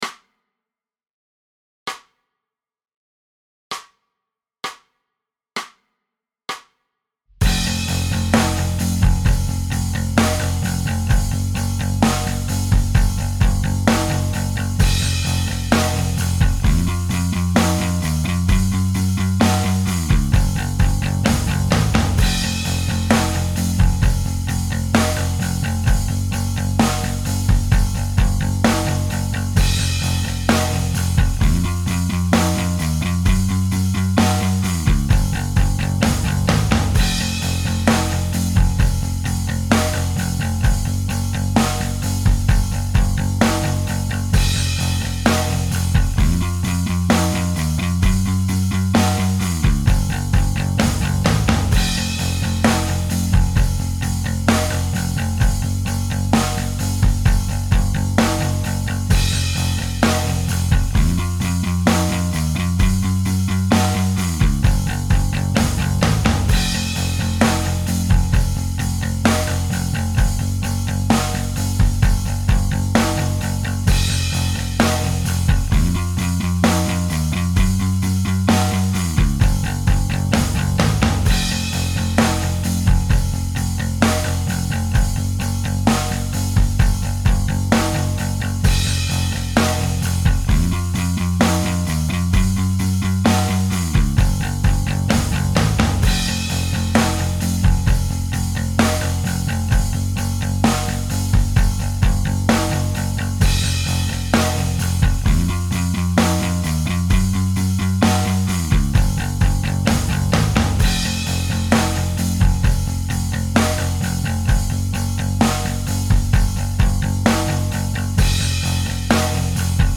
Guitar Lessons: Strumming in Rock